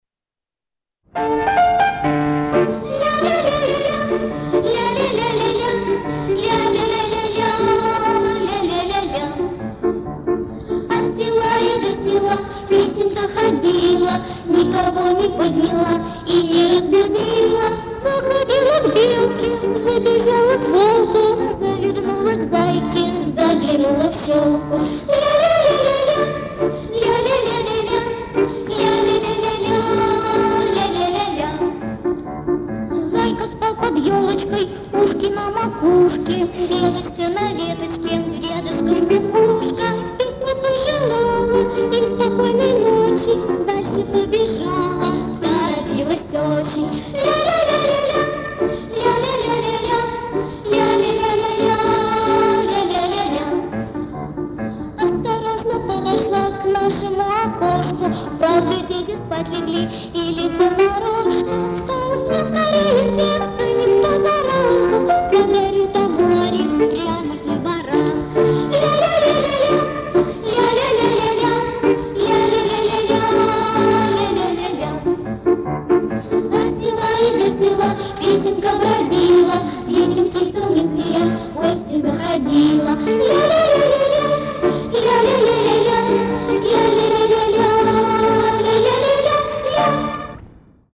Детская песенка